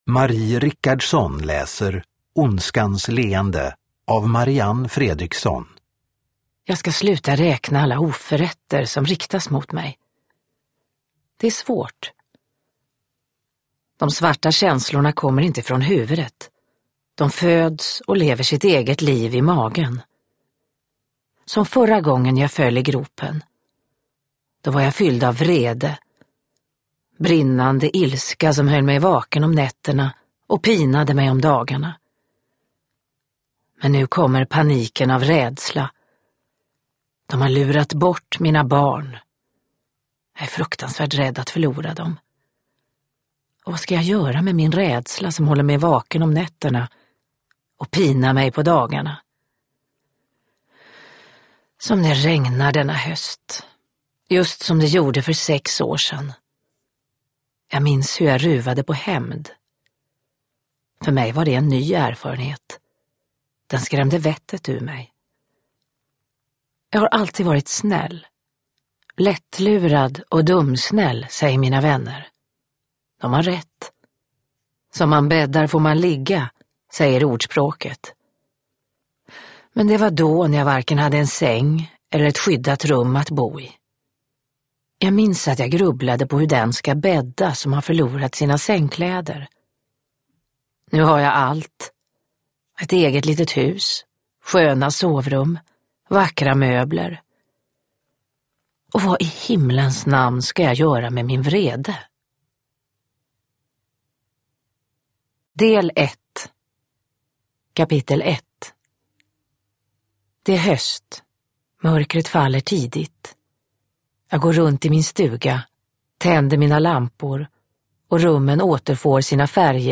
Ondskans leende – Ljudbok
Uppläsare: Marie Richardson